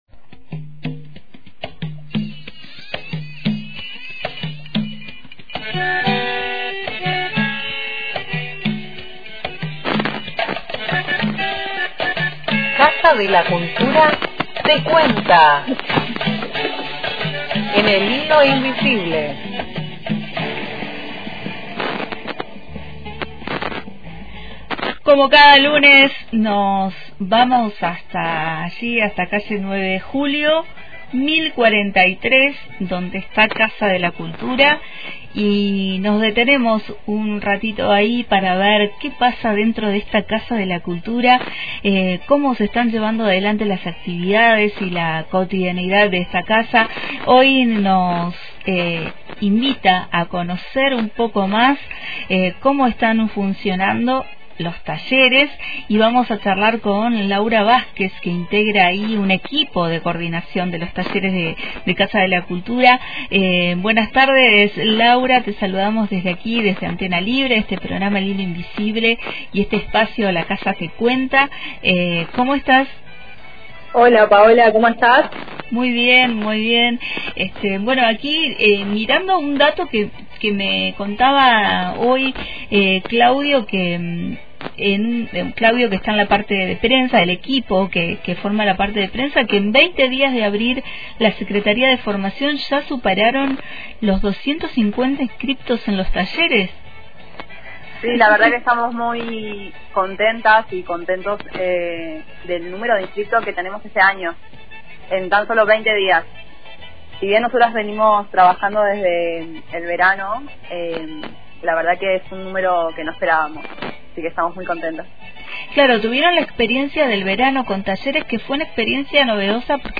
Para conocer más sobre la propuesta de Casa de la Cultura escuchá la nota completa